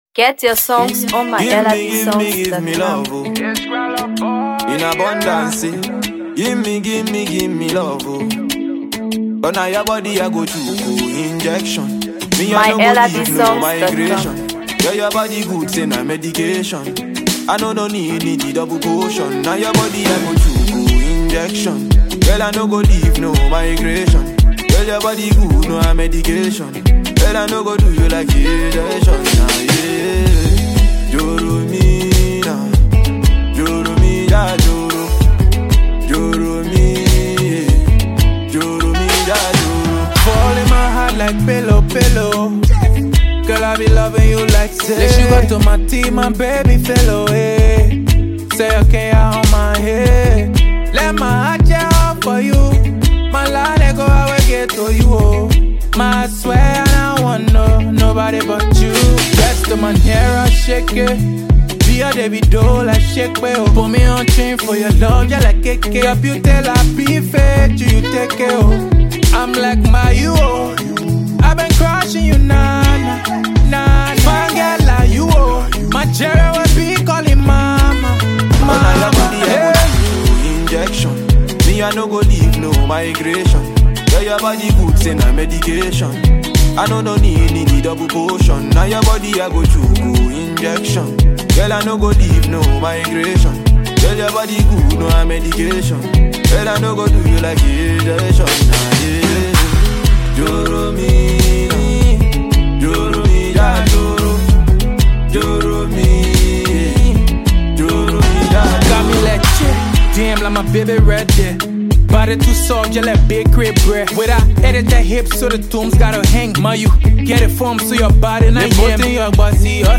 The track blends Afrobeat rhythms with contemporary melodies
With its infectious beat and catchy lyrics